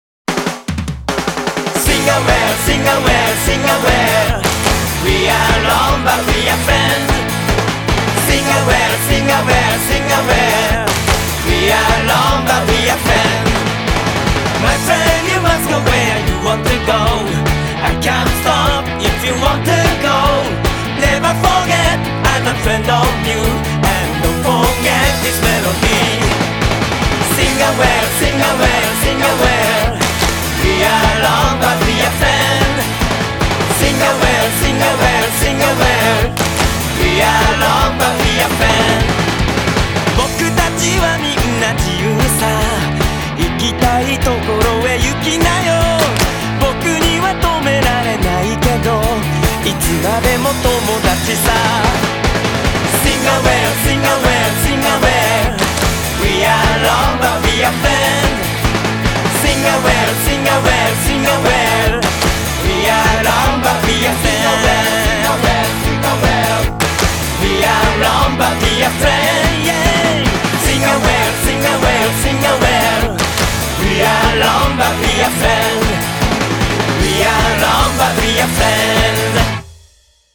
BPM151
Audio QualityPerfect (High Quality)
infectiously catchy Happy Rock song